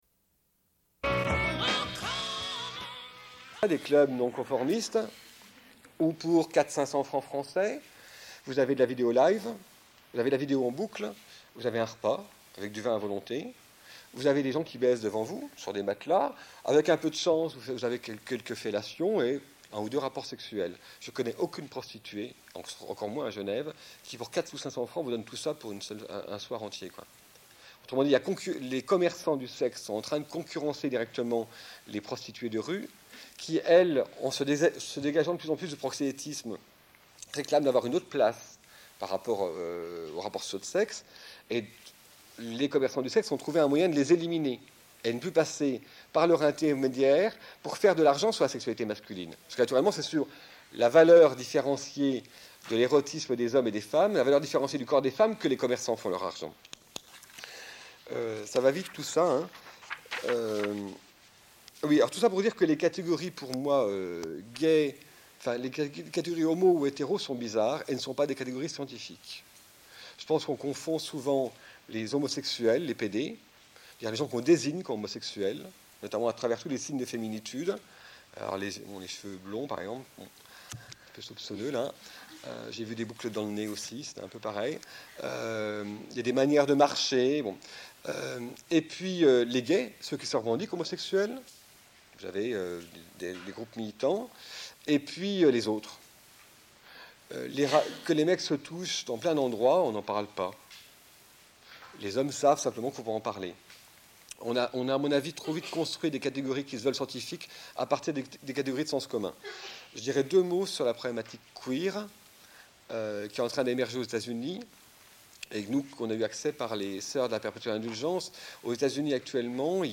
Fin de la conférence puis musique.